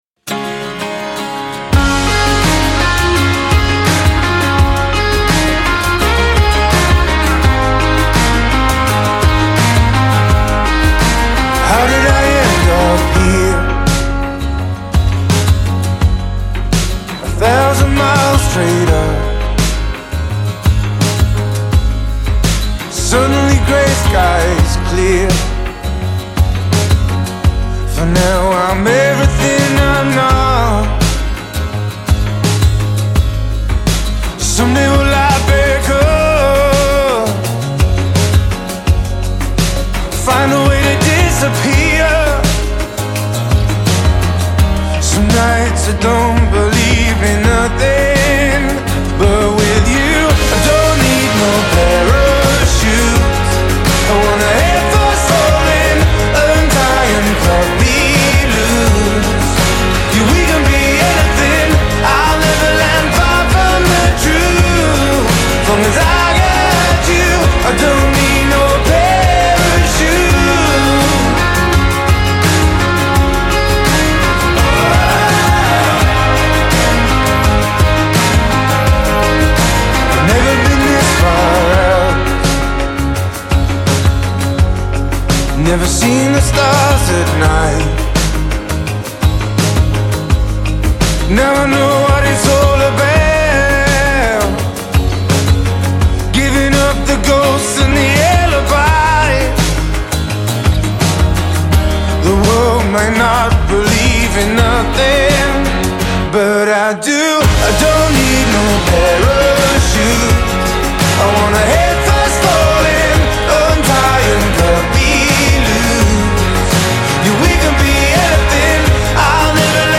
Жанр: foreignbard